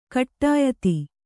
♪ kaṭṭāyati